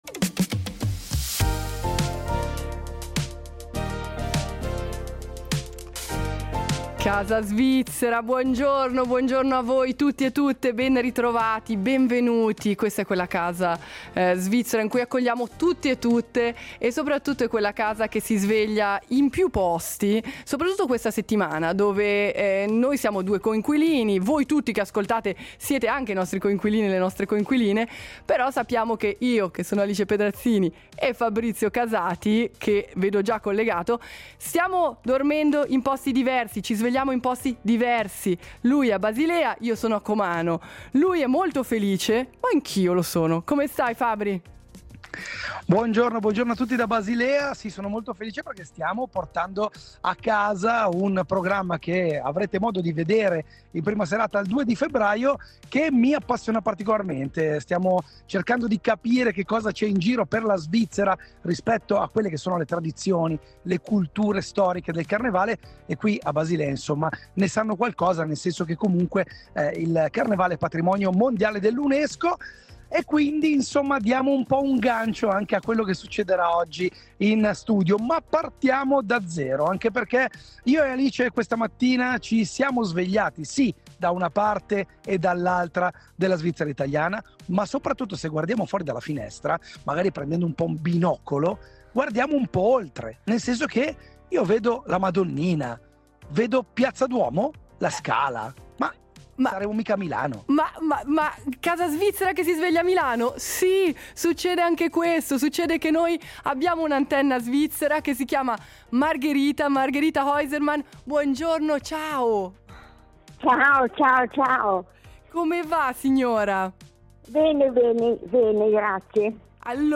Tre CD, quattro ottave di voce, jodel sul Kilimangiaro e sul Monte Fuji… ed è arrivato a trovarci in Casa svizzera, davvero. Perfetto, perché lo yodel potrebbe presto diventare patrimonio UNESCO: la decisione arriverà a dicembre da New Delhi.